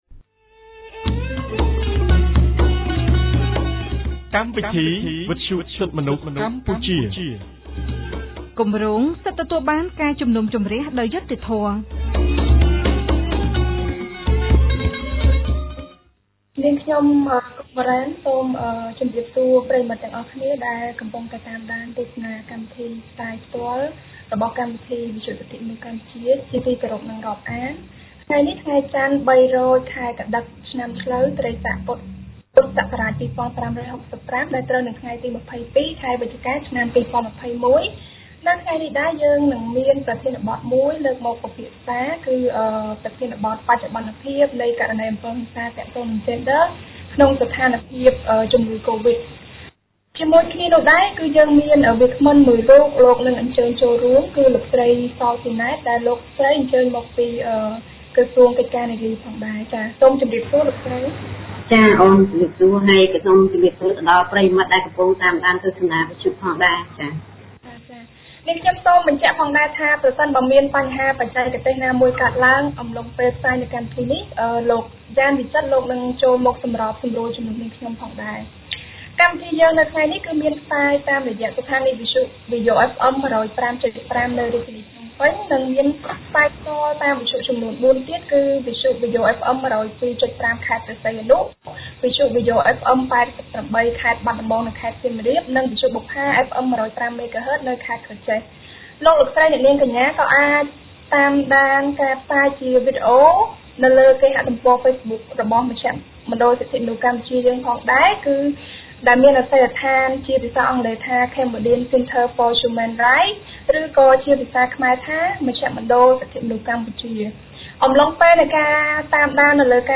ថ្ងៃចន្ទ ទី២២ ខែវិច្ឆិកា ឆ្នាំ២០២១ គម្រាងសិទ្ធិទទួលបានការជំនុំជម្រះដោយយុត្តិធម៌នៃមជ្ឈមណ្ឌលសិទ្ធិមនុស្សកម្ពុជា បានរៀបចំកម្មវិធីវិទ្យុក្រោមប្រធានបទស្តីពី“ស្ថានភាពរបស់ជនរងគ្រោះនៃករណីអំពើហិង្សាទាក់ទងនឹងយេនឌ័រក្នុងអំឡុងពេលនៃការរីករាលដាលជំងឺកូវីដ”